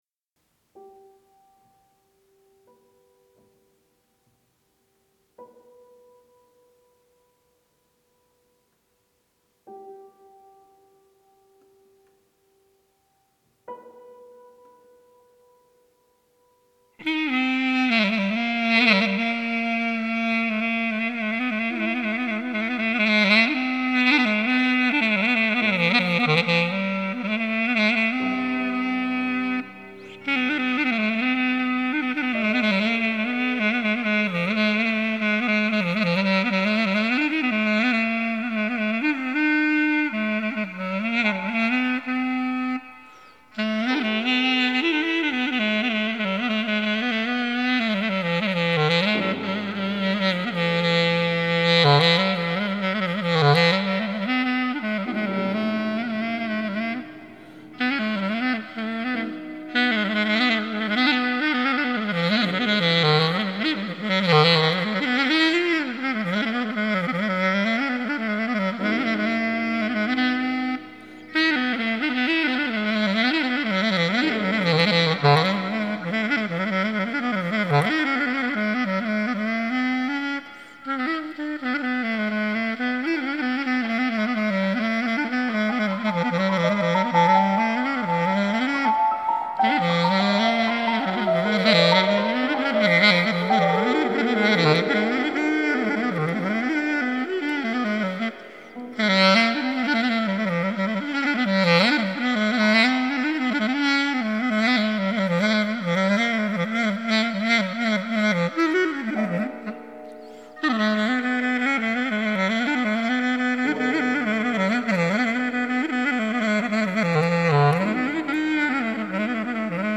low clarinet improvisationby
duet/accompaniment to it live, recordingthe performance.
I then overdubbed his original ( just tomake sure it was clear enough) .
chalumeau_duet.mp3